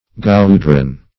Search Result for " goudron" : The Collaborative International Dictionary of English v.0.48: Goudron \Gou`dron"\, n. [F., tar.] (Mil.) a small fascine or fagot, steeped in wax, pitch, and glue, used in various ways, as for igniting buildings or works, or to light ditches and ramparts.